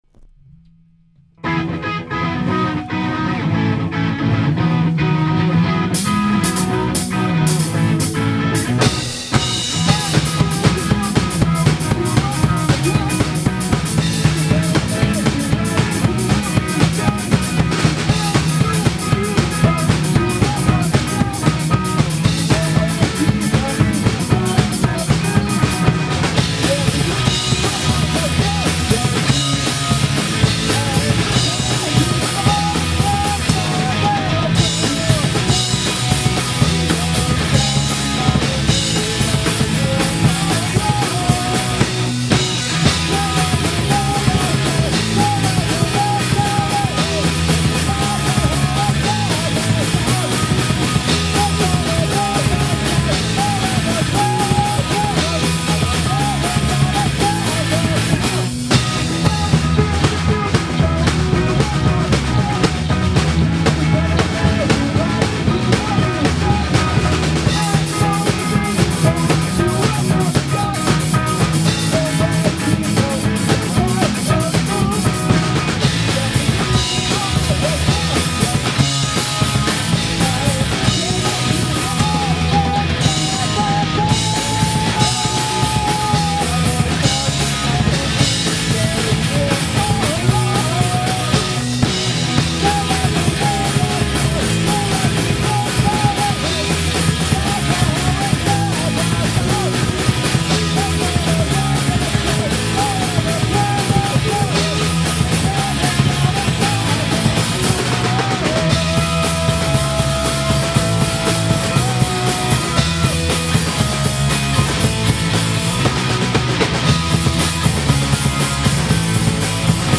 メロコアなのかパンクなのか、3ピースバンド
スタジオ一発録りのDEMO音源と、今までのLIVE動画。